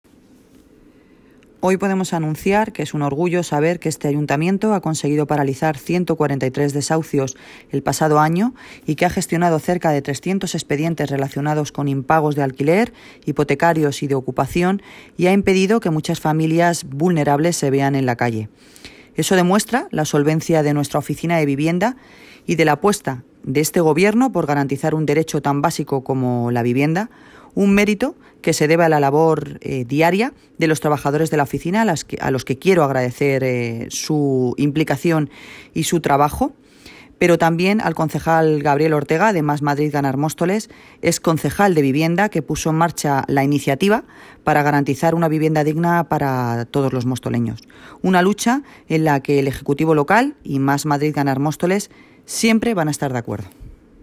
Audio - Noelia Posse (Alcaldesa de Móstoles) Sobre oficina antidesahucios